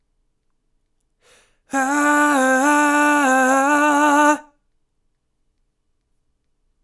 Index of /99Sounds Music Loops/Vocals/Melodies